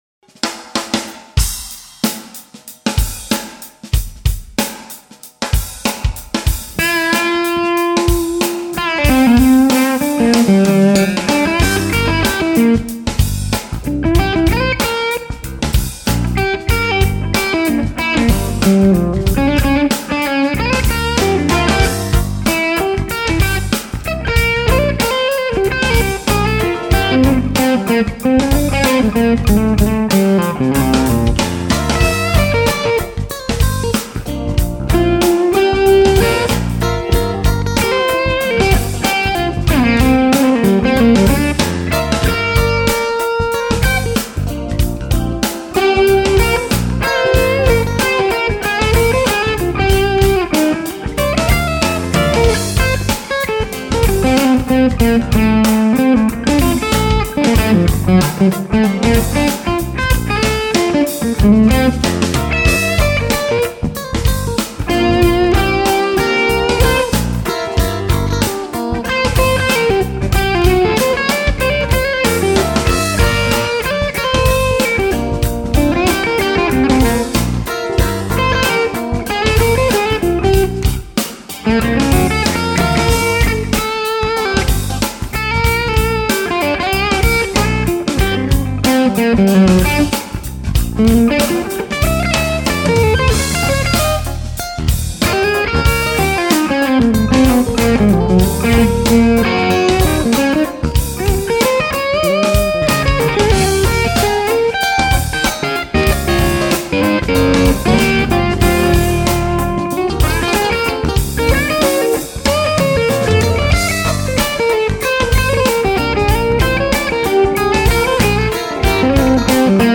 Amp was not too loud.
Sounds absolutely smooth and buttery, great tone!!!